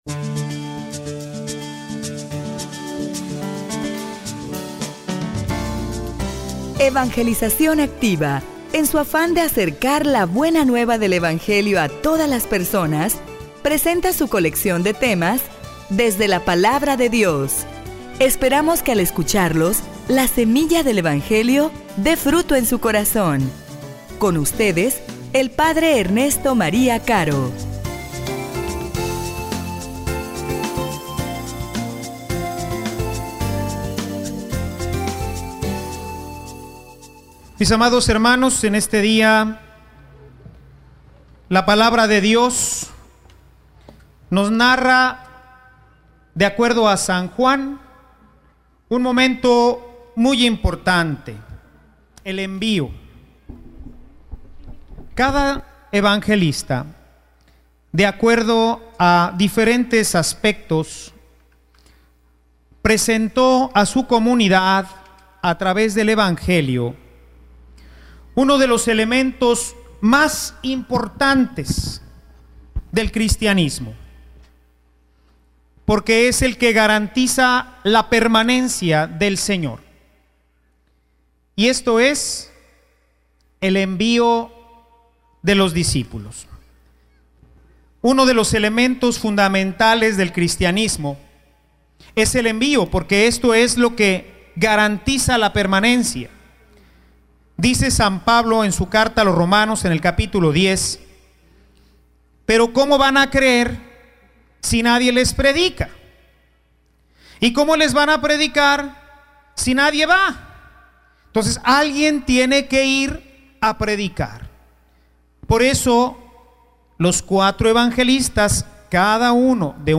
homilia_Enviados_con_poder.mp3